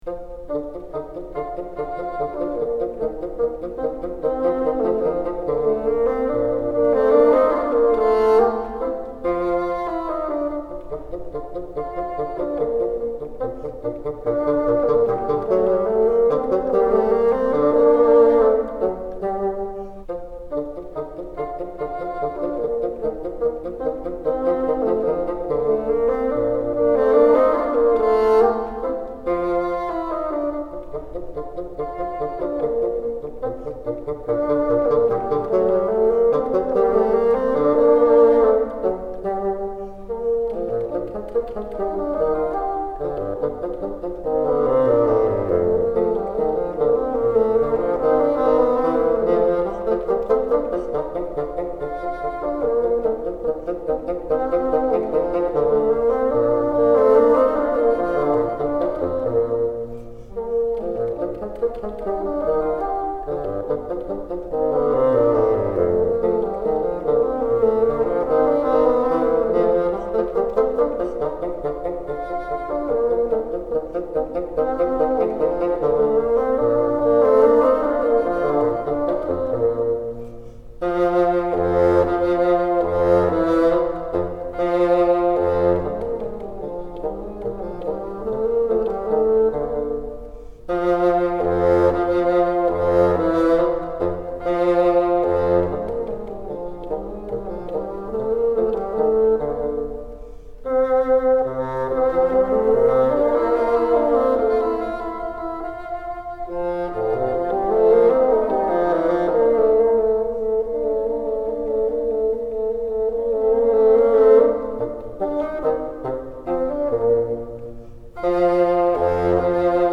Learn both parts to this duet.
Play all eighth notes in this movement staccato unless otherwise indicated.
exercise_46_07_together.mp3